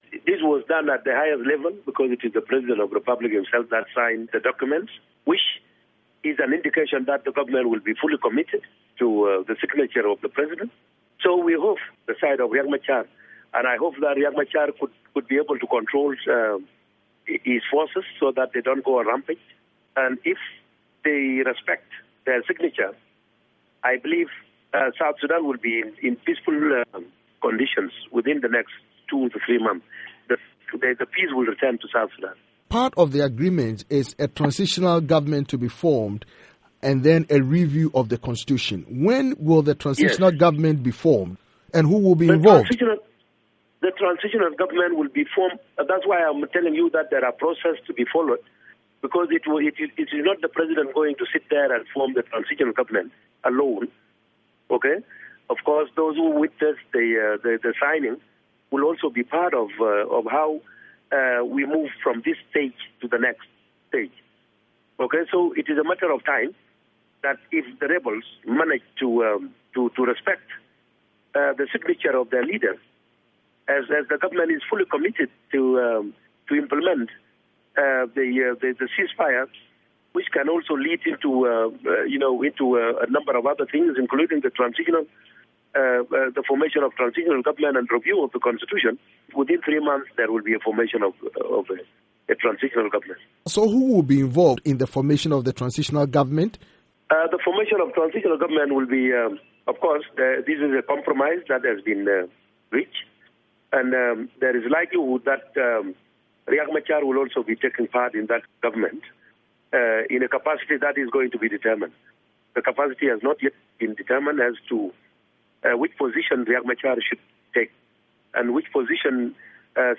interview
Ateny Wek Ateny, presidential spokesperson